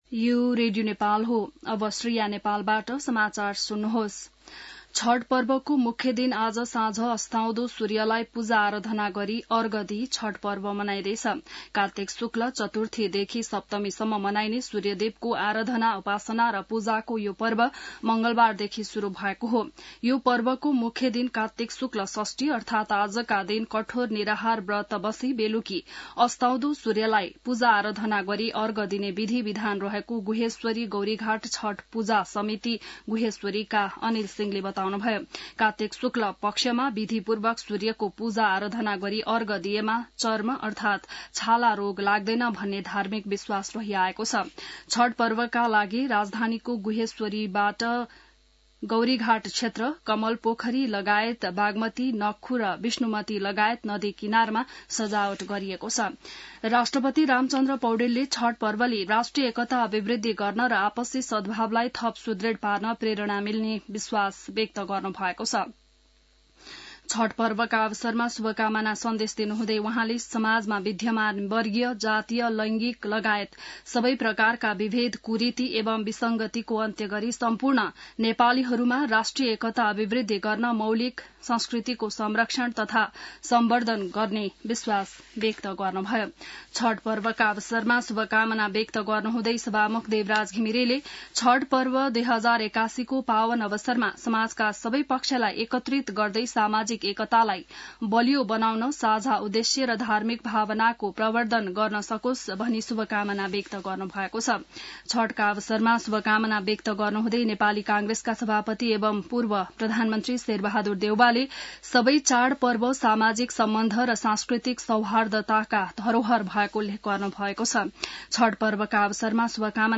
बिहान ६ बजेको नेपाली समाचार : २३ कार्तिक , २०८१